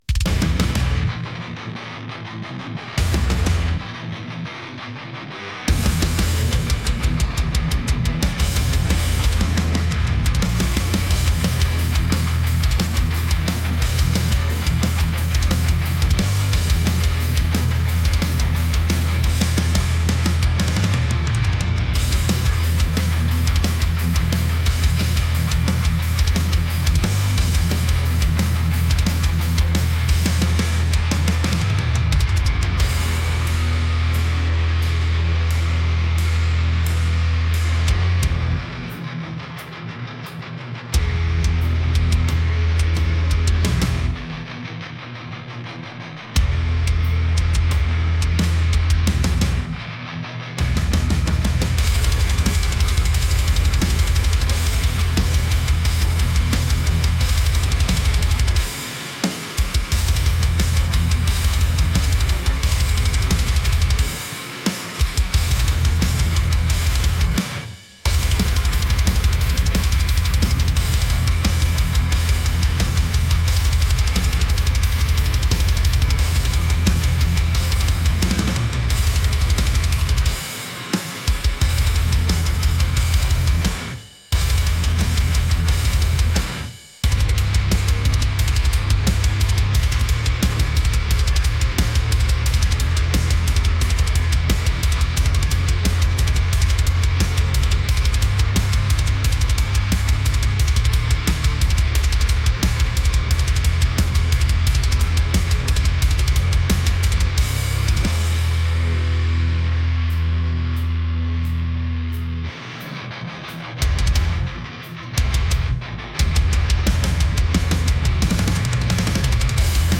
intense | aggressive